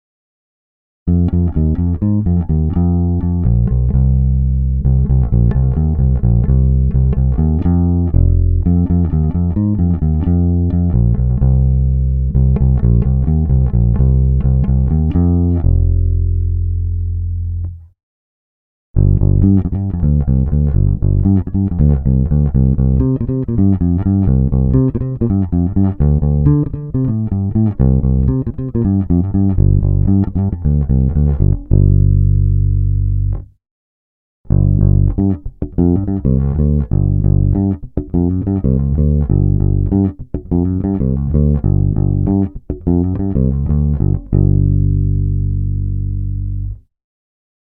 Zvuk je typický Precision.
Následující nahrávku jsem protáhl kompresorem a simulací aparátu. Pořadí ukázek je opět stejné jako nahoře, tedy hra u krku, nad snímačem a nakonec mezi snímačem a kobylkou.
Ukázka se simulací aparátu – no řekněte, nehraje to krásně?